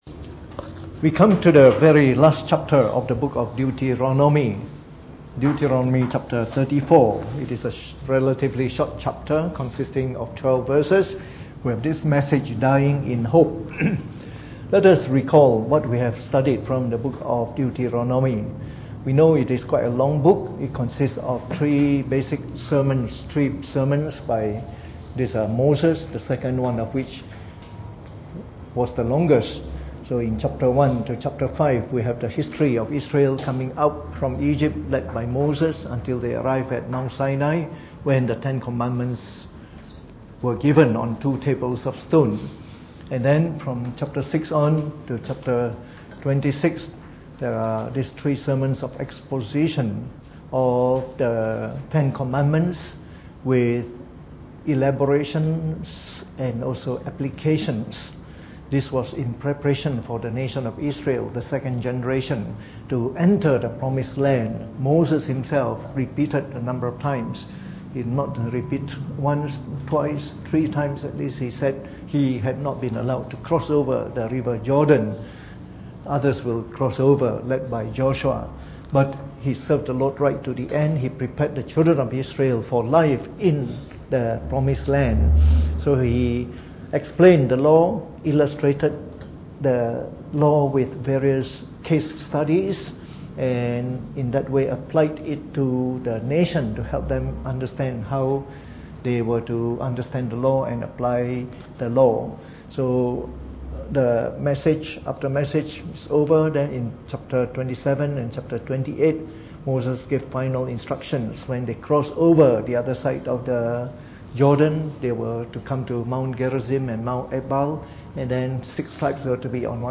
Preached on the 31th of October 2018 during the Bible Study, from our series on the book of Deuteronomy.